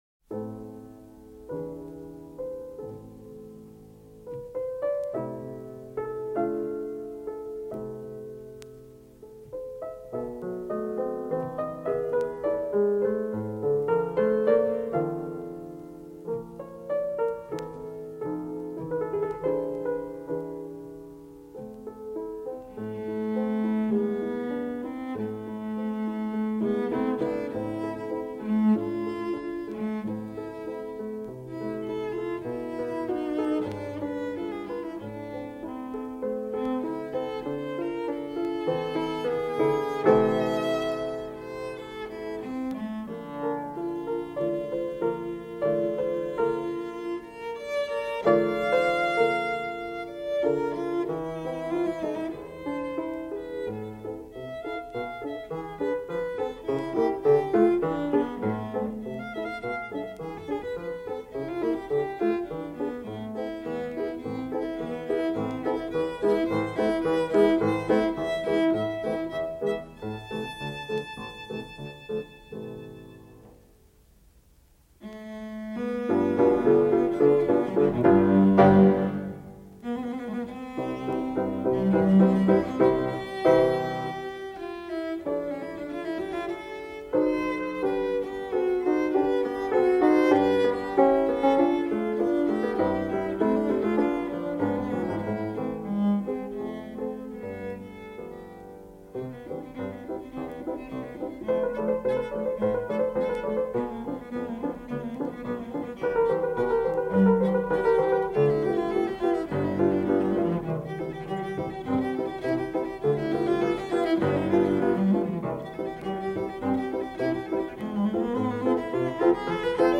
Arpeggione, Anton Mitteis zugeschrieben, Leitmeritz, 2.
arpeggione.mp3